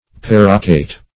Paroquet \Par"o*quet`\, n. [F. perroquet, or Sp. periquito; both